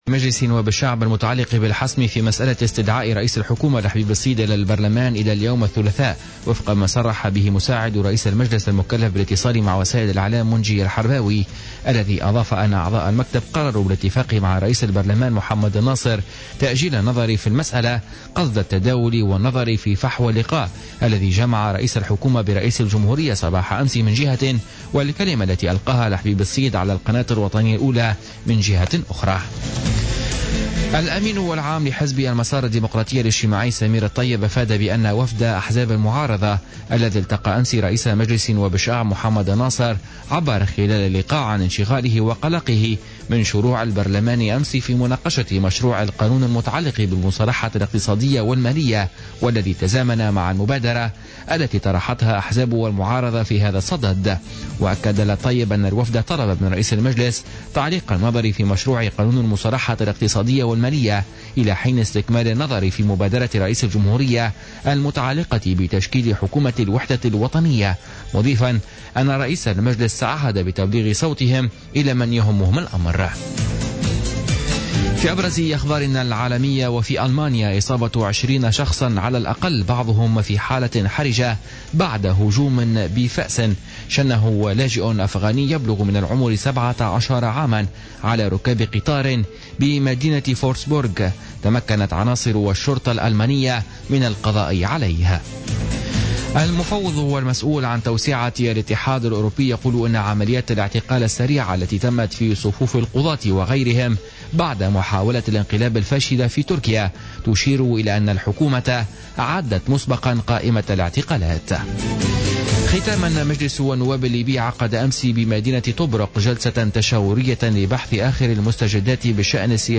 نشرة أخبار منتصف الليل ليوم الثلاثاء 19 جويلية 2016